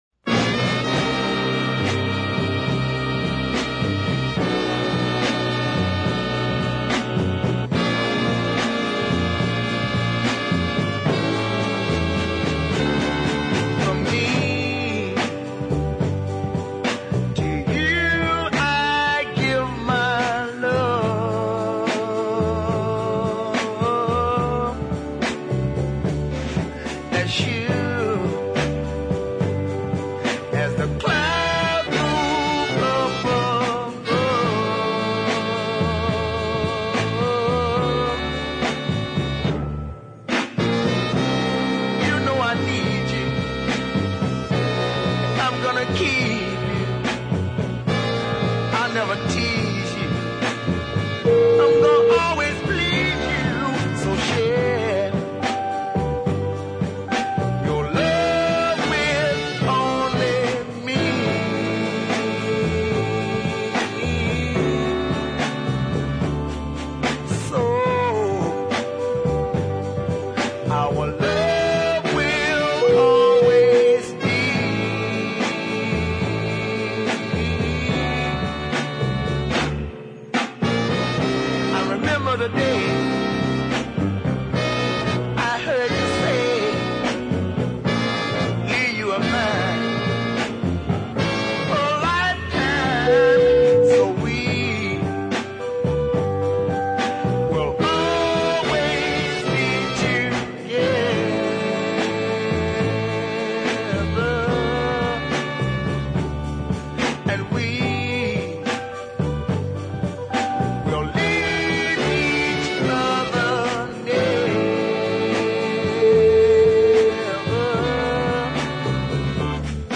is a bluesy deep soul ballad of power and emotion
tortured, gospel drenched tones